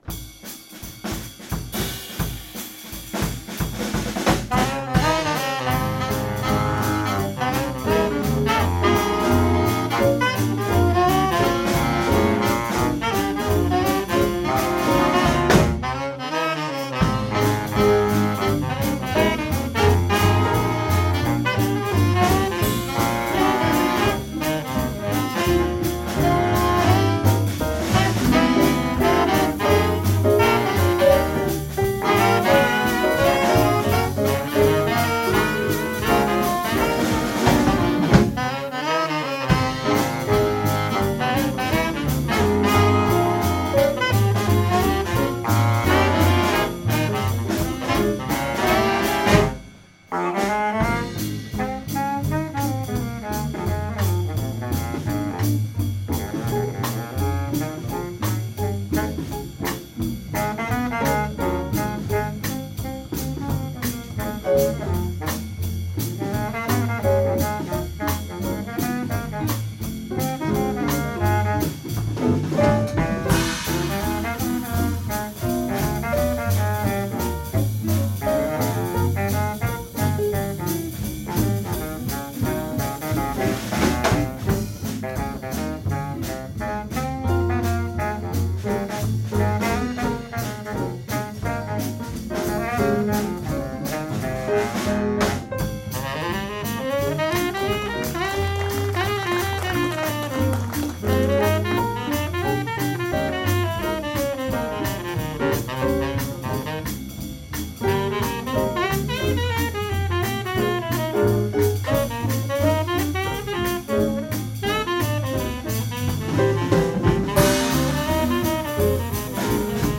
Jazz-nonetten på Lautrupgaard - optagelser 2025
Lyd i mp3-format, ca. 192 kbps, optaget stereo - ikke studieoptagelser!.